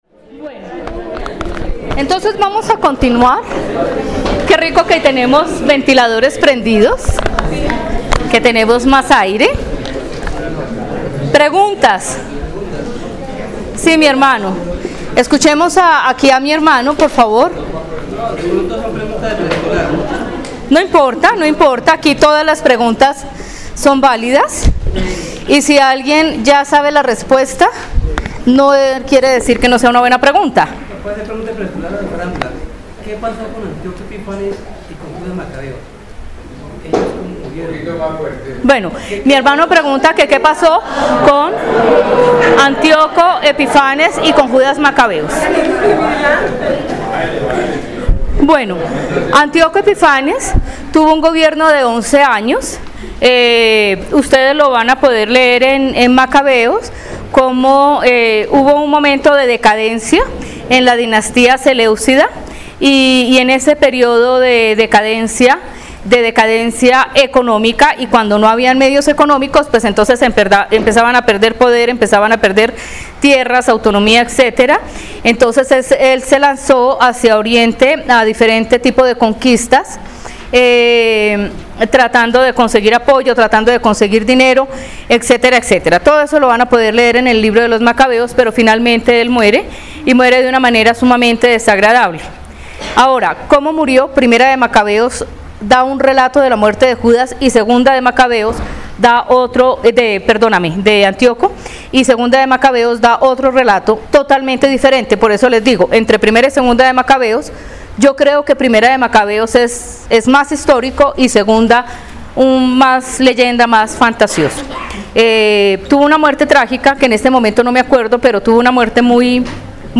Lección 1: Introducción a la historia del cristianismo IV (Marzo 10, 2018)